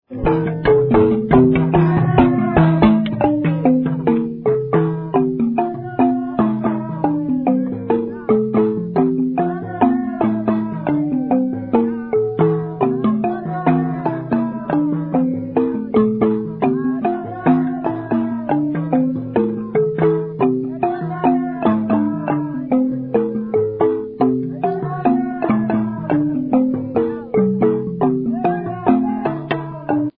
Lambani family (Performer)
Traditional music
Mbila
Xylophone
Instrumental
Traditional Venda tune accompanied by the 21 key xylophone
Cassette tape